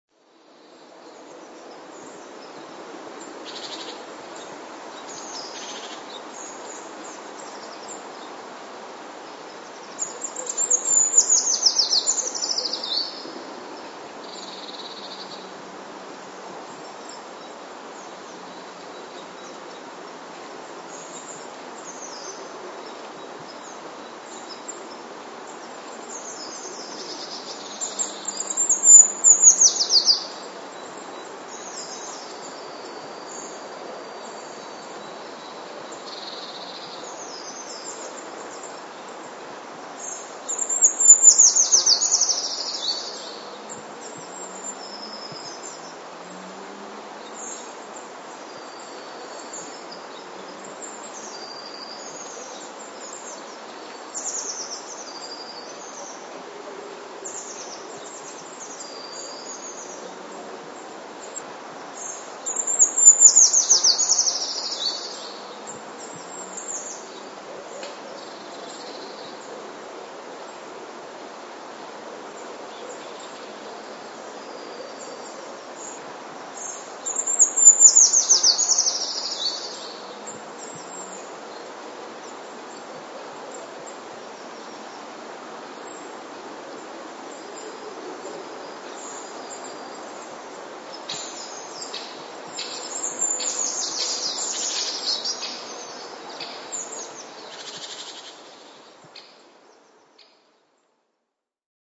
Pełzacz leśny - Certhia familiaris
pełzacza leśnego siedemnastego stycznia. Tego dnia była odwilż, więc dzień był dość ciepły. śpiew tego pełzacza składa się z serii krótkich piosenek, oddzielonych od siebie wyraźnymi przerwami.
Składa się z wysokich, melancholijnych gwizdów, o opadającej tonacji i zakończona jest szybkim trylem. Śpiew ten brzmi czysto, niezgrzytliwie.
Trzecia jest żwawym trelem, o opadającej tonacji, zakończonym pojedynczym, modulowanym, mniej lub bardziej wgłębionym gwizdem.